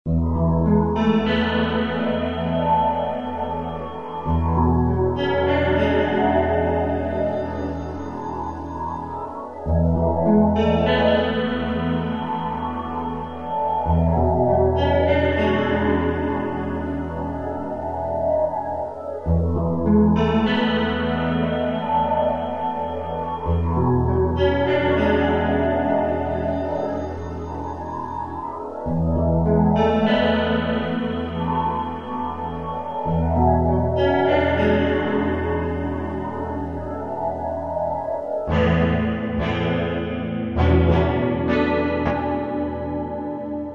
Музыкальный хостинг: /Рок